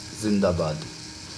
Zindabad_pronunciation.ogg.mp3